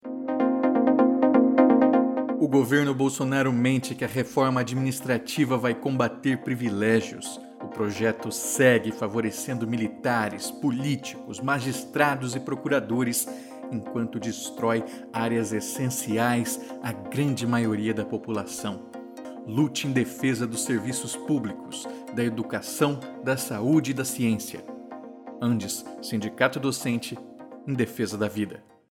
Também estamos entrando com inserções de áudio em redes sociais, com os spots abaixo, realizados pela equipe de comunicação das seções gaúchas do ANDES-SN, que reúne Adufpel, Aprofurg, Sedufsm, Sesunipampa, Seção da UFRGS e SindoIF, com apoio da Regional RS.
Spot1_PEC32-1.mp3